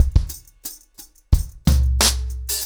ROOTS-90BPM.25.wav